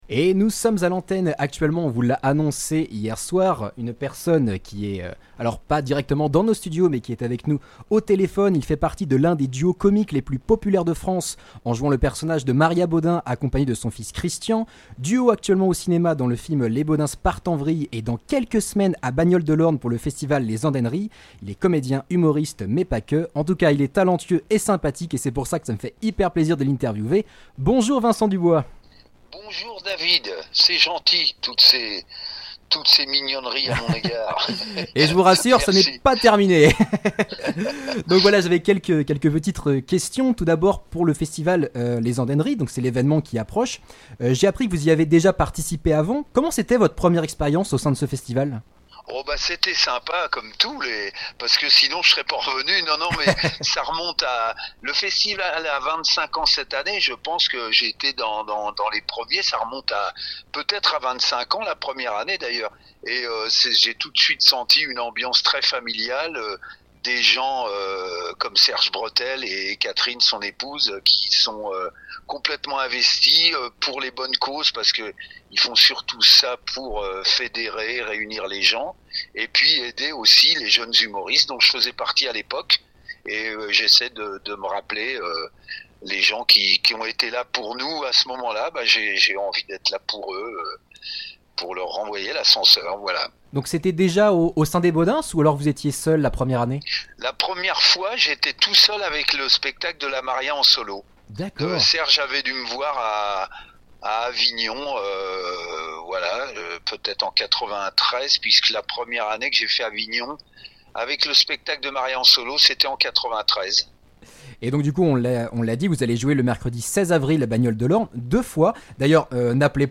Interviews RCDF